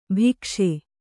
♪ bhikṣe